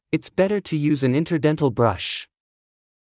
ｲｯﾂ ﾍﾞﾀｰ ﾄｩ ﾕｰｽﾞ ｱﾝ ｲﾝﾀｰﾃﾞﾝﾀﾙ ﾌﾞﾗｯｼ